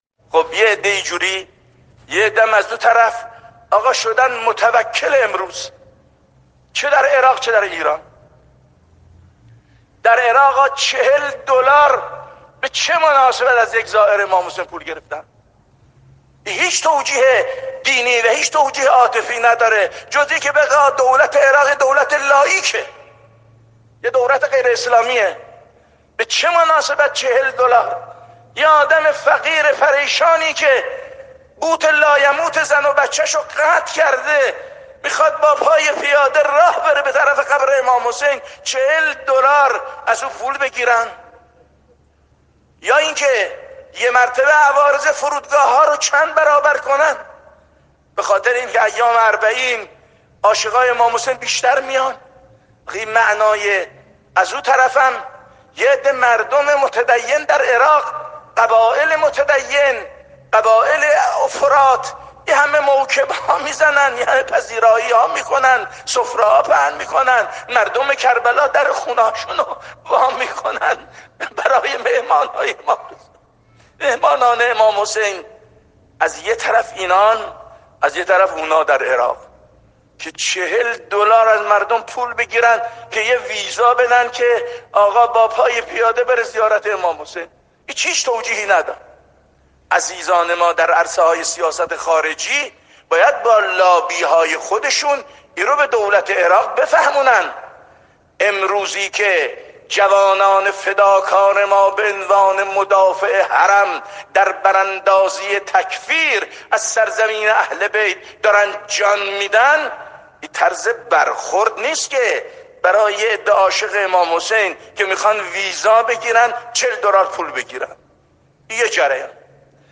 در ادامه انتقادات آیت‌الله علم‌الهدی را می‌شنوید: